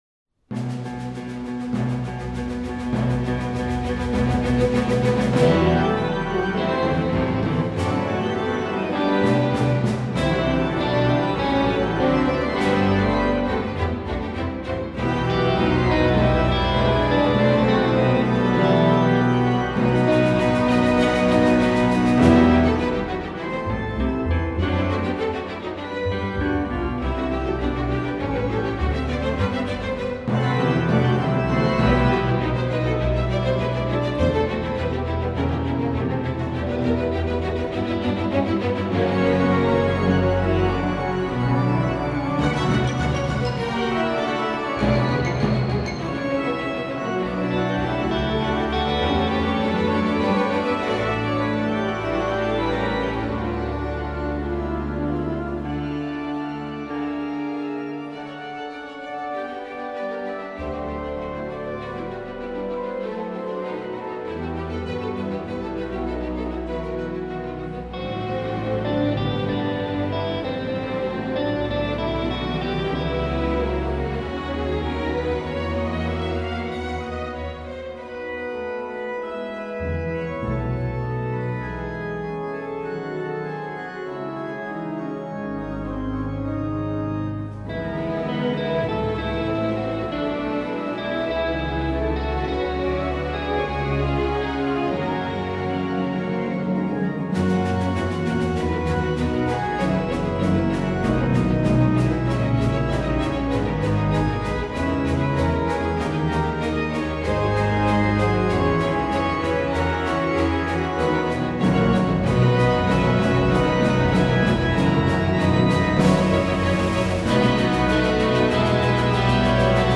organist